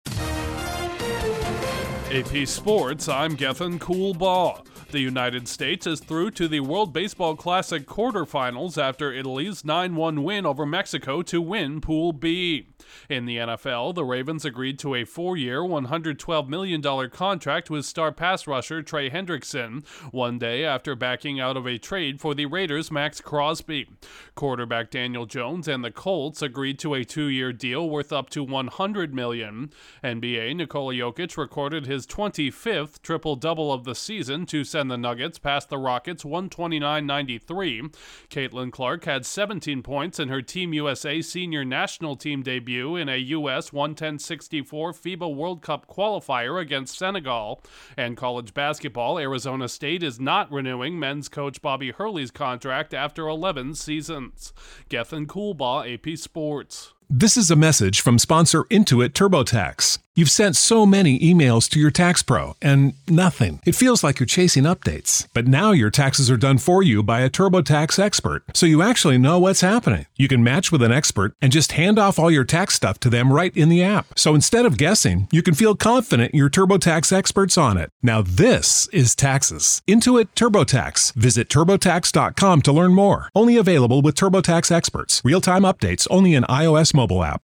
Team USA survives at the World Baseball Classic with some help, the Ravens add a star pass rusher after backing out of a deal for another, a quarterback drafted No. 6 overall signs a multi-year extension, a three-time NBA MVP notches another triple-double, the top women’s hoops star debuts with Team USA and a longtime college basketball coach is headed to free agency. Correspondent